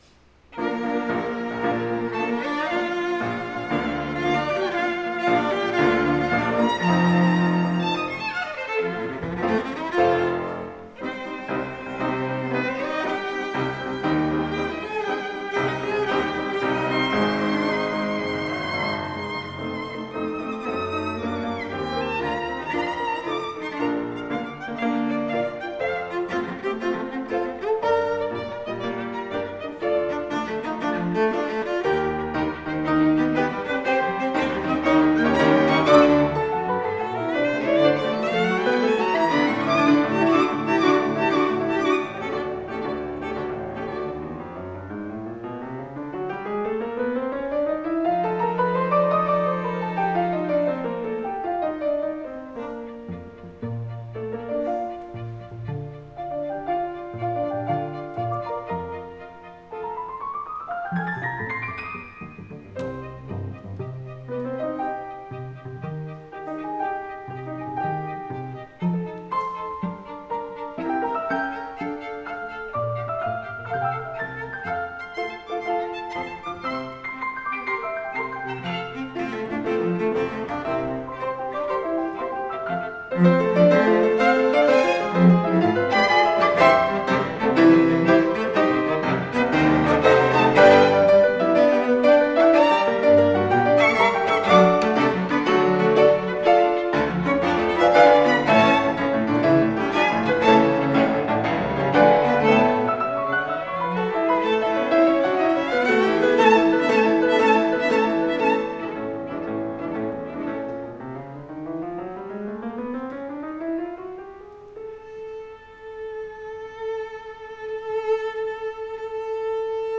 the students
Chamber, Choral & Orchestral Music
2:00 PM on July 26, 2015, St. Mary Magdalene
Schubert Piano Trio #1
16 schubert piano trio.mp3